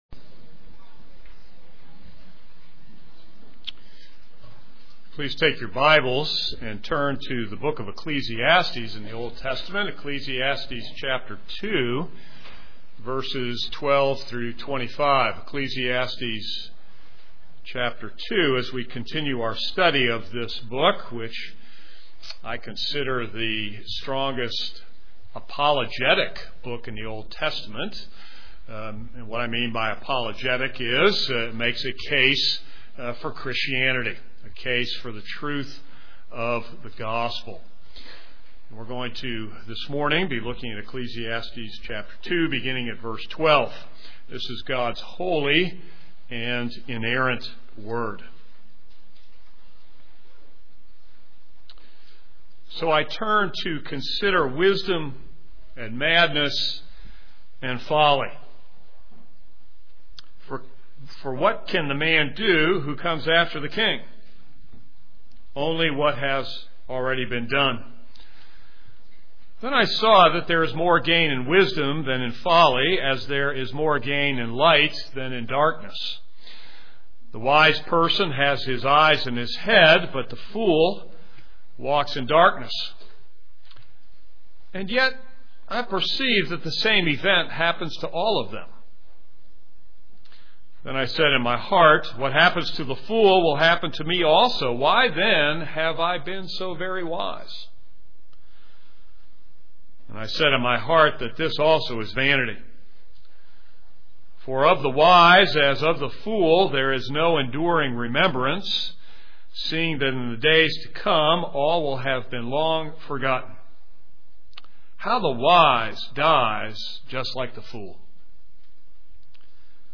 This is a sermon on Ecclesiastes 2:12-26.